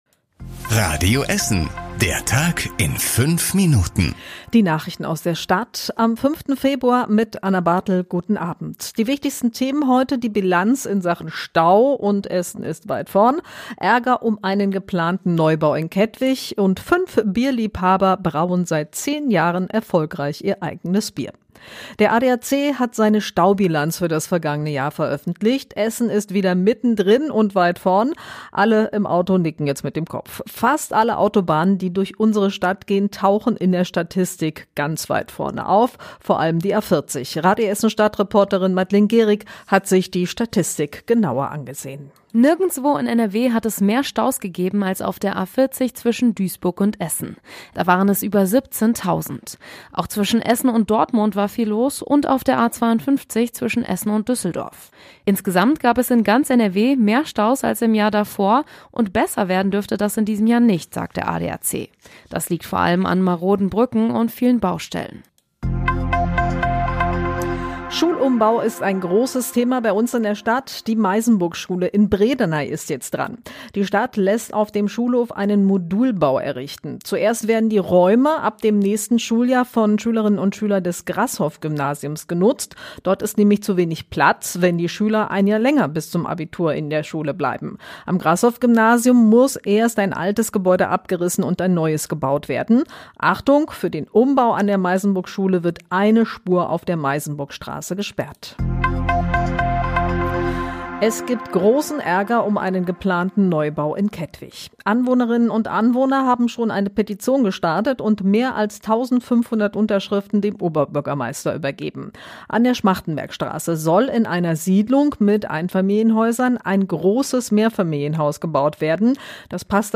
Die wichtigsten Nachrichten des Tages in der Zusammenfassung